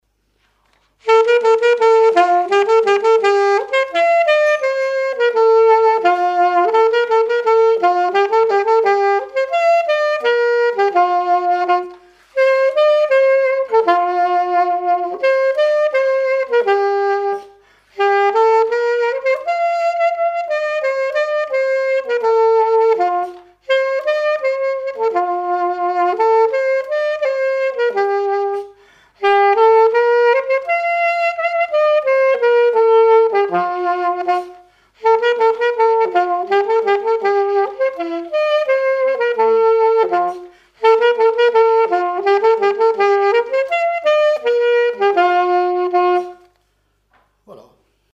Vendée
circonstance : fiançaille, noce
Pièce musicale inédite